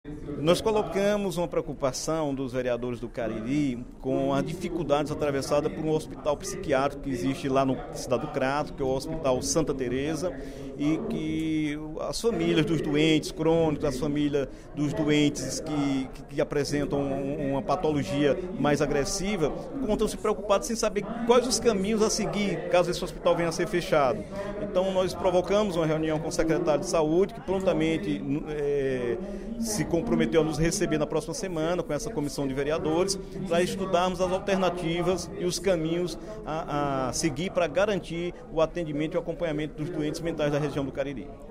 O deputado Dr. Santana (PT) manifestou preocupação, nesta quinta-feira (15/10), durante o primeiro expediente da sessão plenária, com o possível fechamento do Hospital Santa Tereza, do Crato, referência na área psiquiátrica.